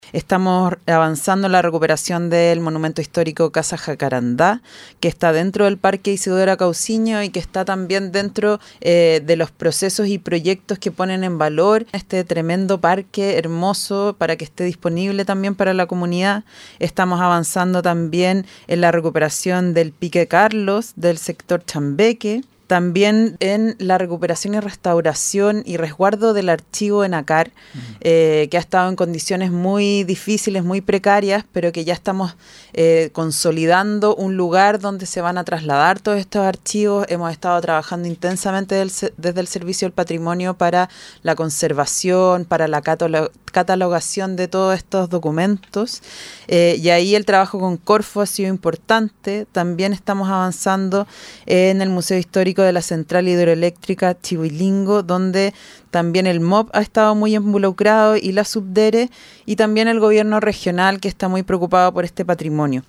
En entrevista con Nuestra Pauta, la ministra de las Culturas, las Artes y el Patrimonio, Julieta Brodsky Hernández, se refirió a la situación presupuestaria de la cartera para este 2023, a la implementación del ‘Plan cultural 2023-2025’ y a otros hitos regionales, como fue la reapertura del monumento histórico nacional ‘Chiflón del Diablo’.